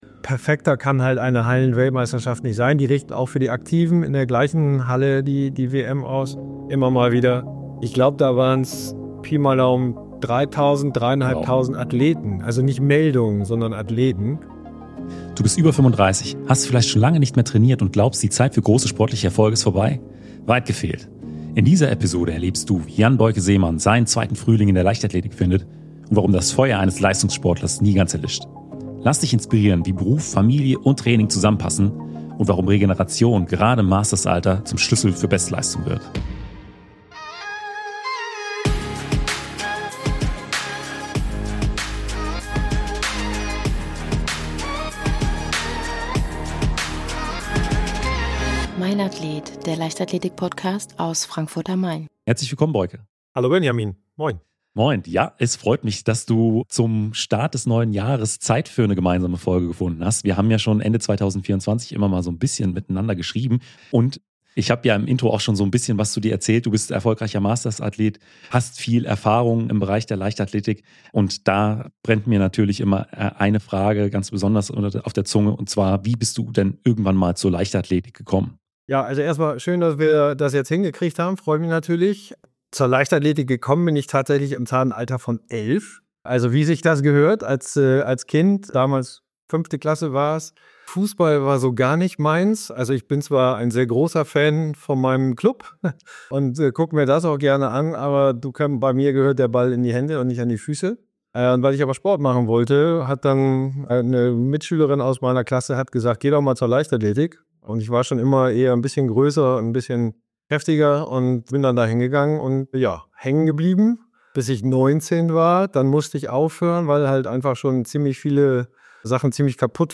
Im Mainathlet Leichtathletik Podcast geht es rund um die Leichtathletik und die Athleten aus allen Disziplinen. Ich werde regelmäßig aktive und ehemalige deutsche Leichtathleten und Leichtathletinnen interviewen. Außerdem lasse ich Trainer und Unterstützer zu Wort kommen.